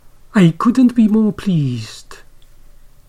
|aɪ ˈkʊdnt bi ˈmɔː ˈpliːzd|